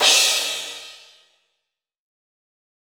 Crashes & Cymbals
HDDCRASH.WAV